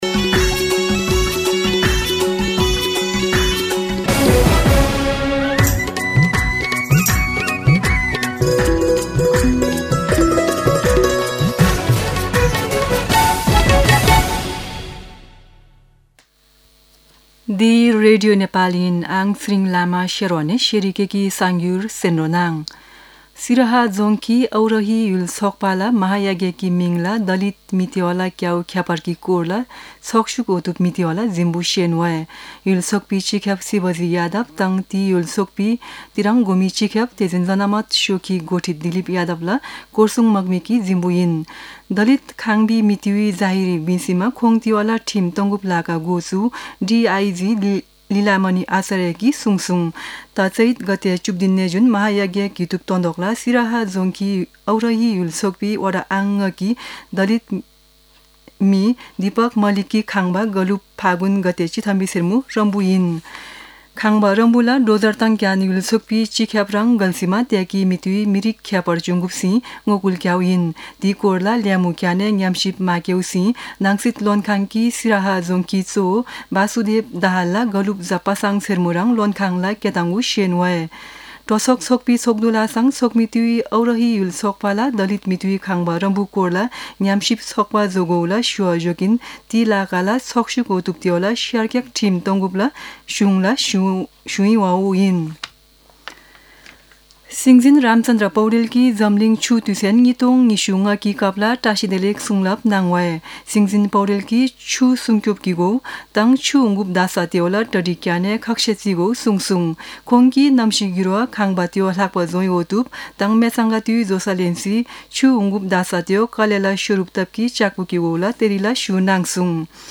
शेर्पा भाषाको समाचार : ९ चैत , २०८१
Sherpa-News-12-9.mp3